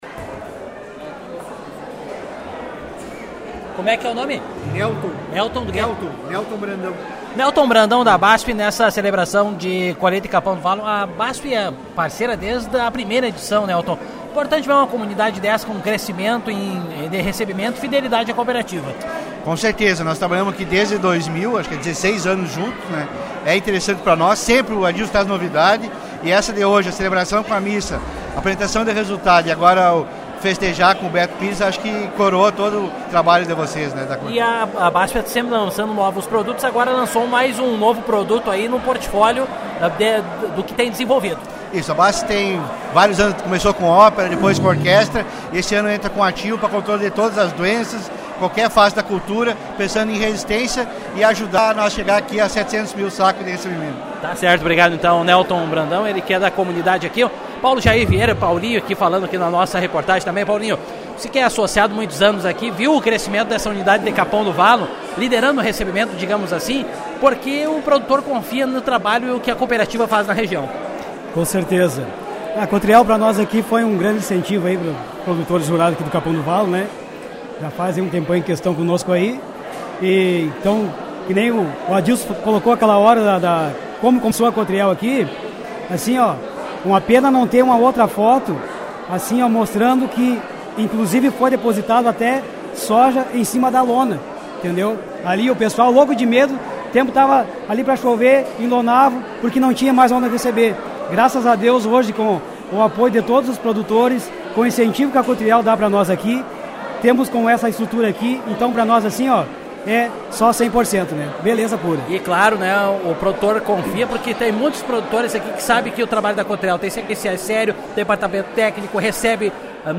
Mais de 200 pessoas presentes na 7ª Celebração da Colheita em Capão do Valo